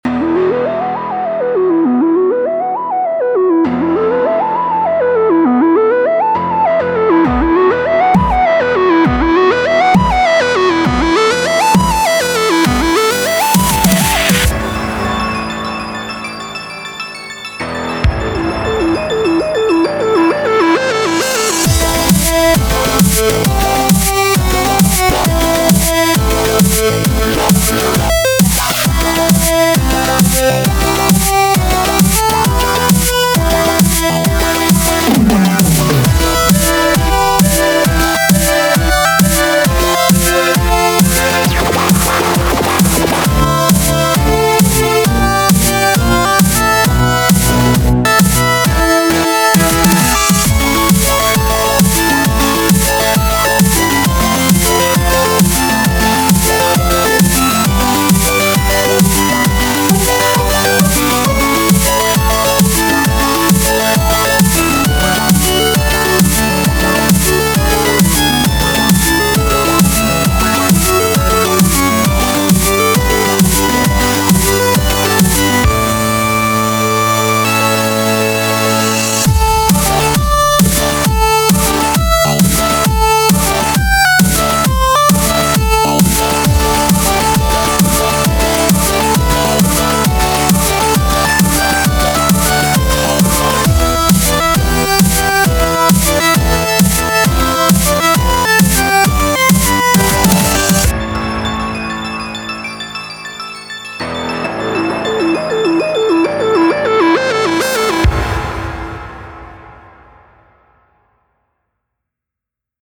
Video Game Remixes
BPM133
Audio QualityMusic Cut